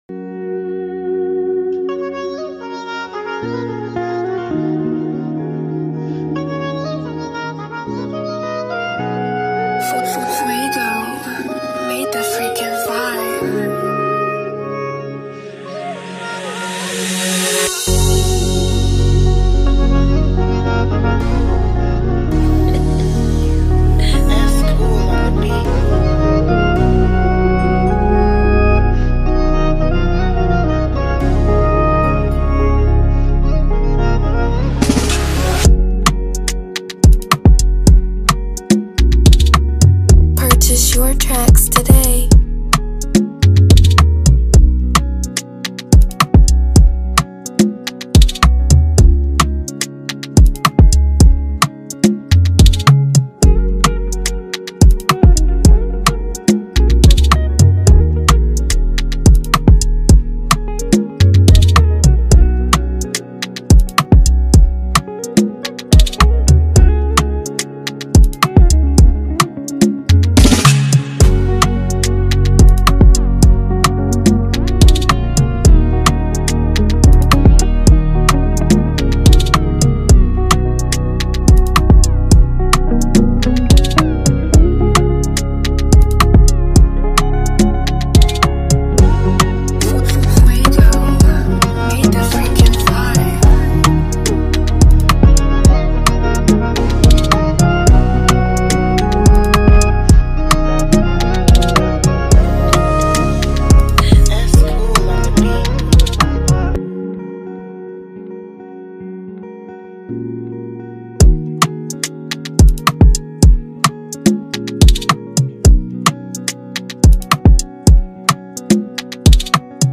vibrant Afrobeat instrumentals
With its pulsating rhythms and cultural influences